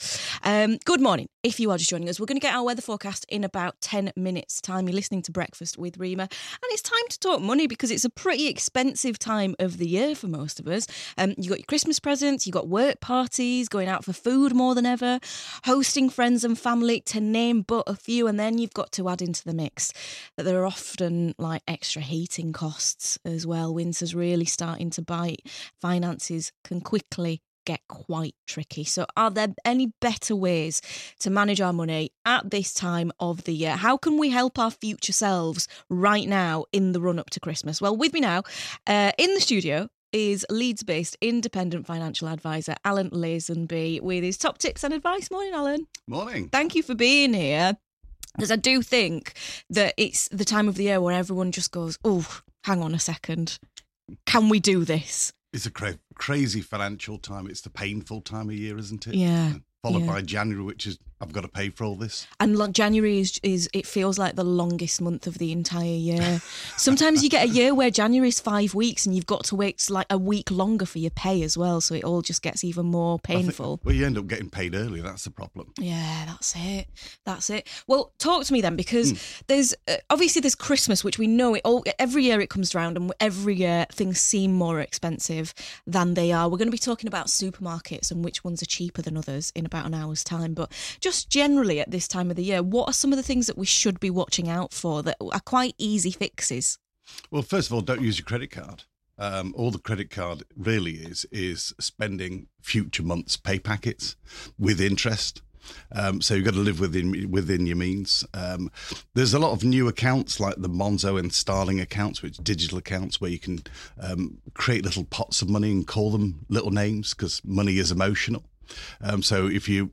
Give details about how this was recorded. on BBC Radio Leeds to discuss how couples can avoid money matters becoming an issue in relationships.